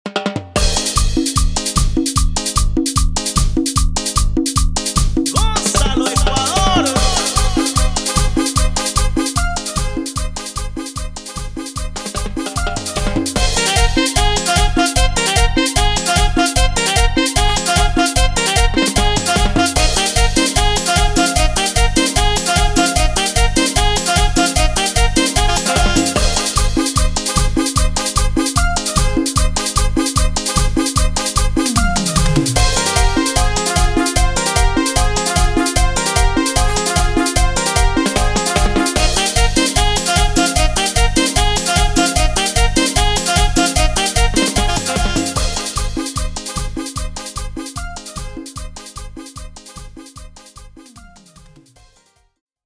Nacional